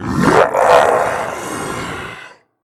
hell_dog2.ogg